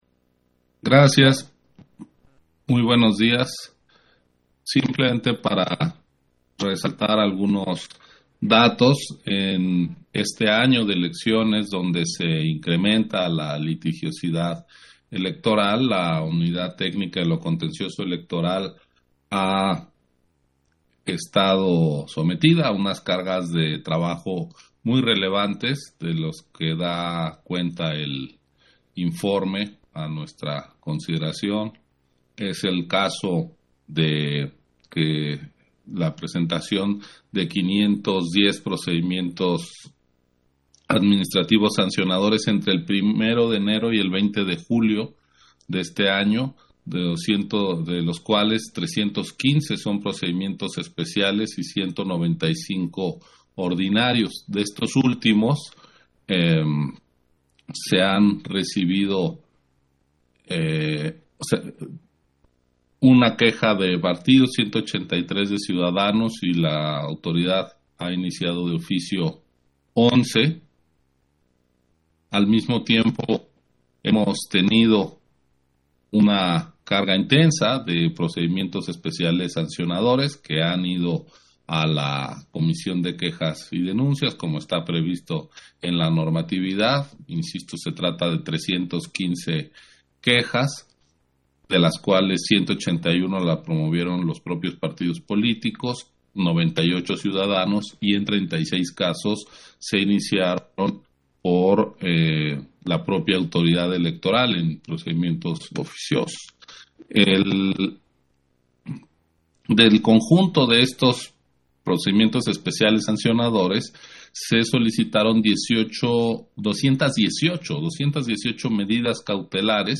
280721_AUDIO_INTERVENCIÓN-CONSEJERO-MURAYAMA-PUNTO-3-SESIÓN-ORD. - Central Electoral